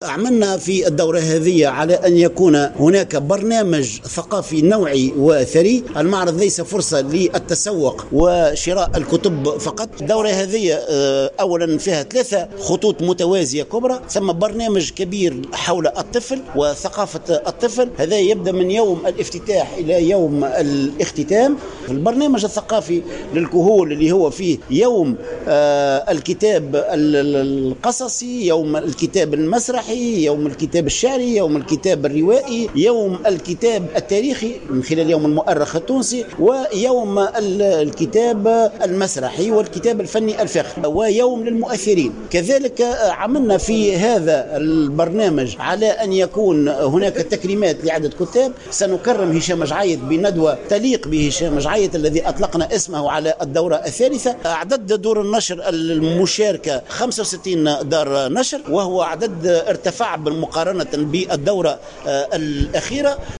تصريح للجوهرة اف ام